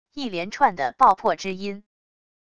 一连串的爆破之音wav音频